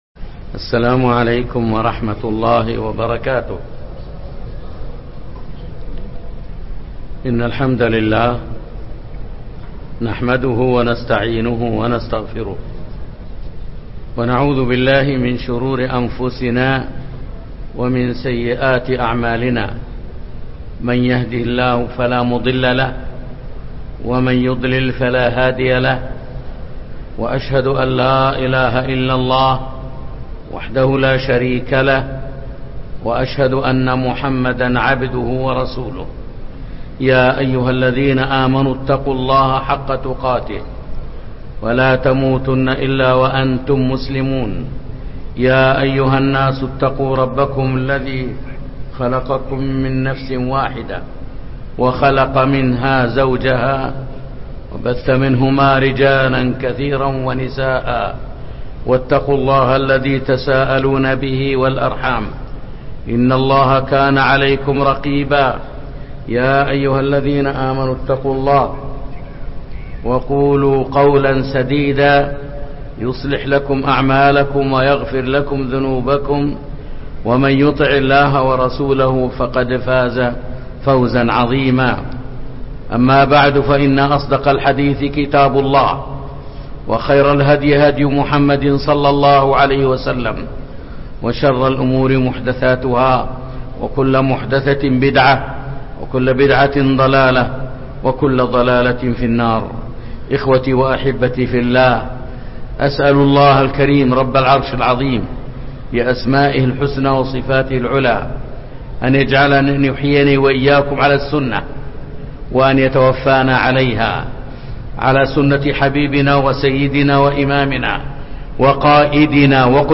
محاضرة
مسجد قباء